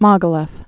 (mŏgə-lĕf, mə-gĭ-lyôf)